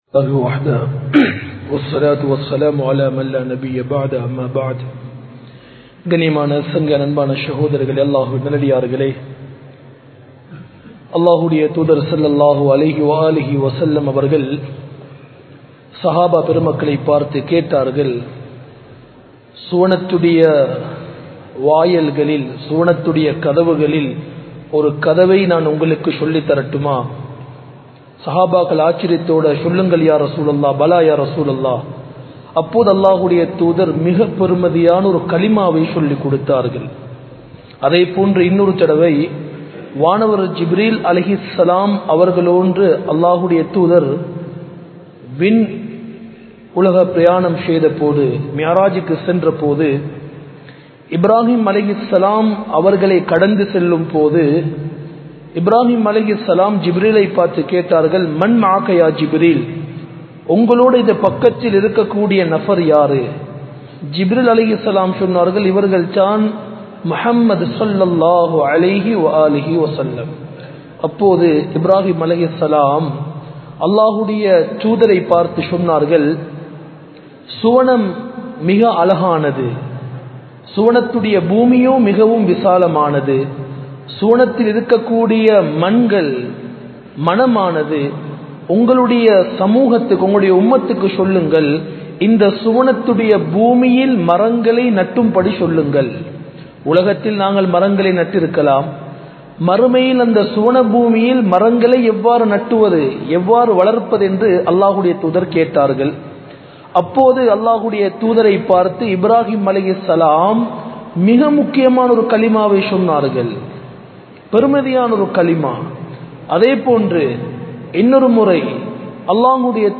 Bayans
Colombo 04, Majma Ul Khairah Jumua Masjith (Nimal Road)